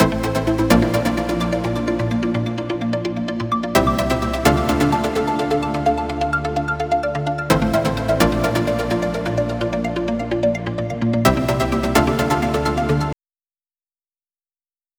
まずは、ひとつめの素材を作りました。シンセパートです。
シンセ音を3個レイヤーして書き出してます。